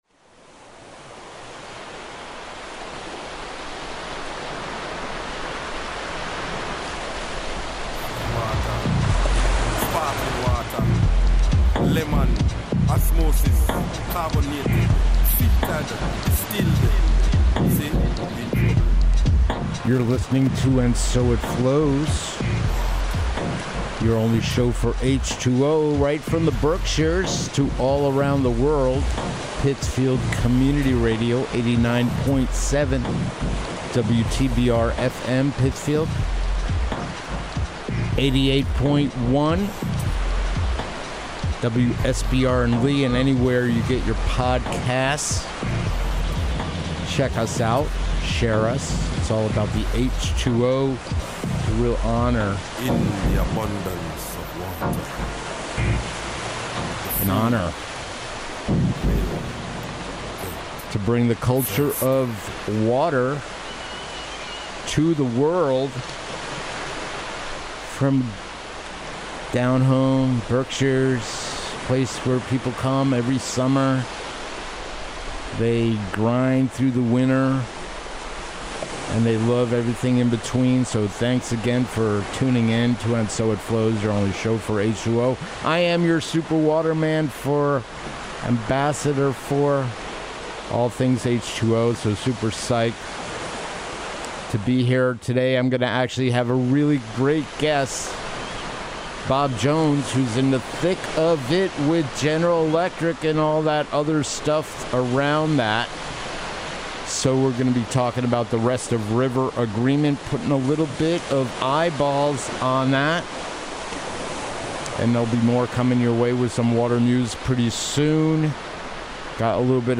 Play Rate Listened List Bookmark Get this podcast via API From The Podcast 'And So It Flows' combines our unique blend of water music, water news, water culture and deep water talk.